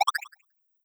Success3.wav